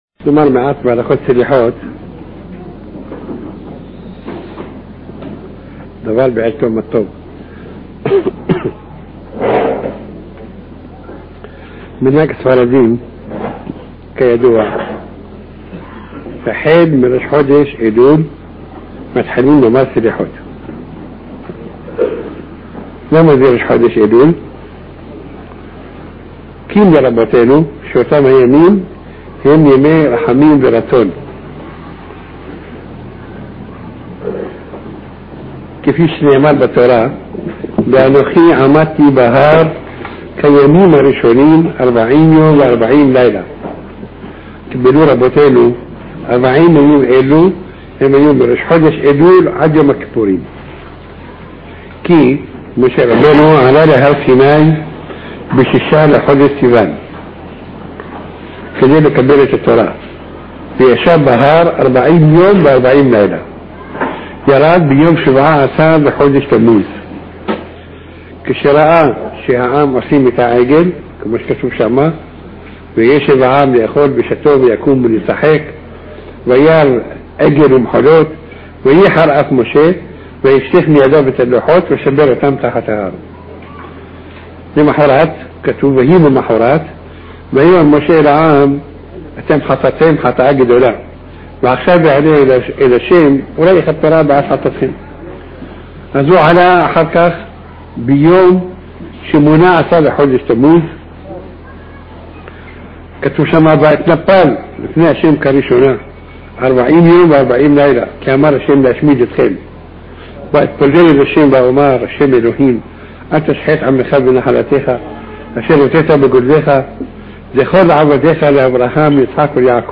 שיעור מיוחד בהלכות סליחות מפי מרן הרב עובדיה יוסף זצ״ל — ביאור מעמיק ומעשי להכנה רוחנית לימים הנוראים.